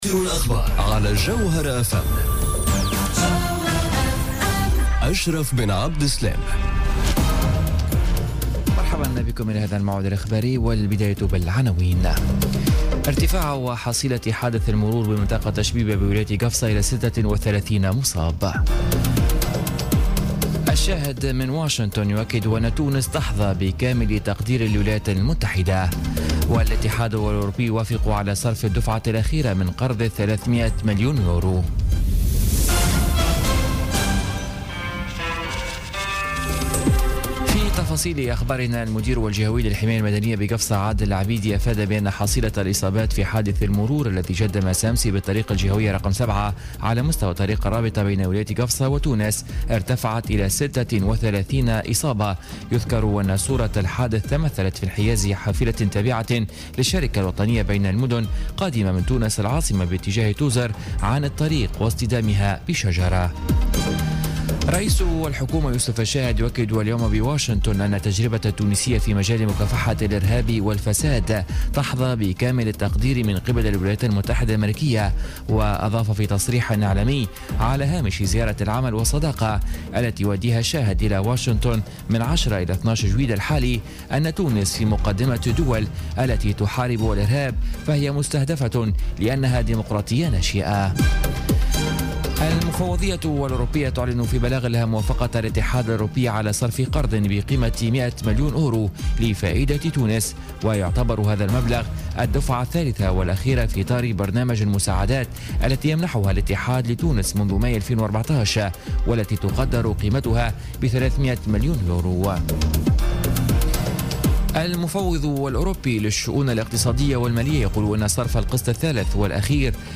Journal Info 00h00 du mardi 11 juillet 2017